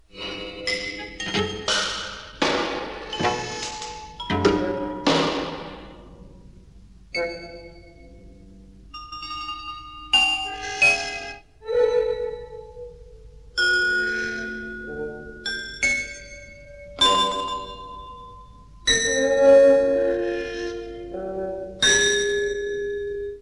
cello, trombone, vibraphone, 3 percussion [22']